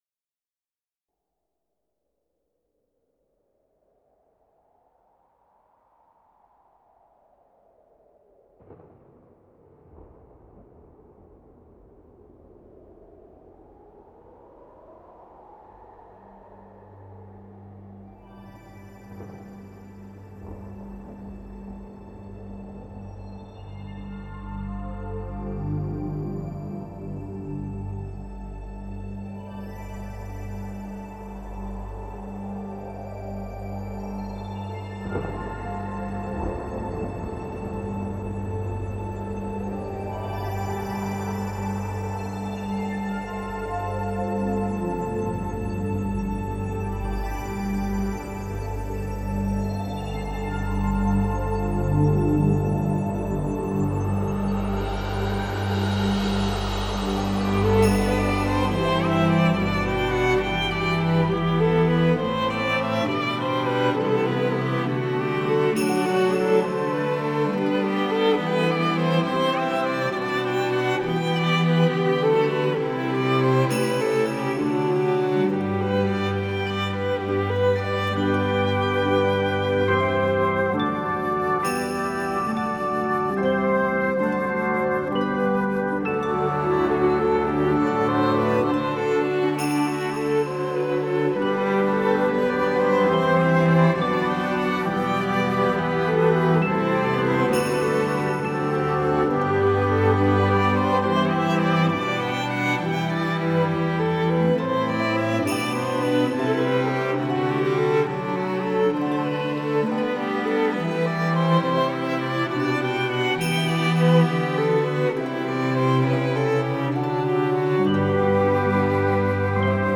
Нью эйдж New age Музыка для медитации